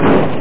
Amiga 8-bit Sampled Voice
Crash2.mp3